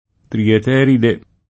trieterico [triet$riko] agg. (stor.); pl. m. ‑ci — «ricorrente ogni terzo anno (ossia un anno sì e uno no)», detto di feste dell’antica Grecia — anche trieteride [